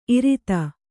♪ irita